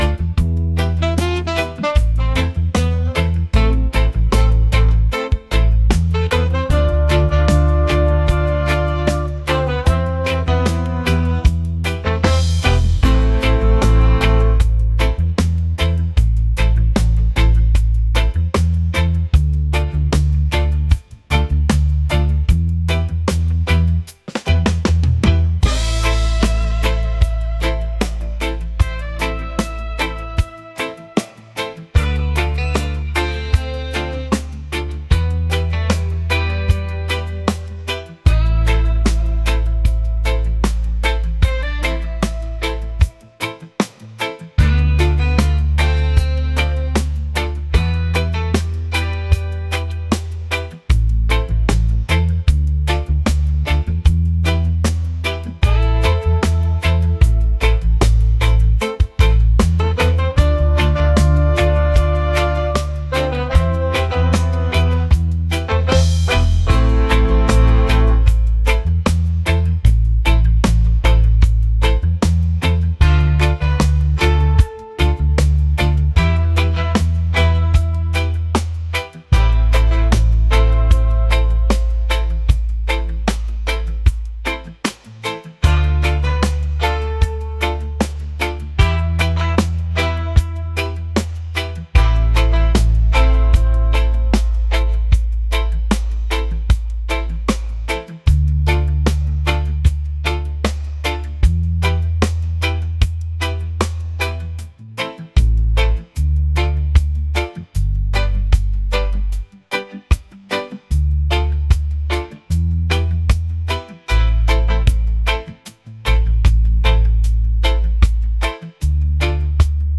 mellow | romantic | reggae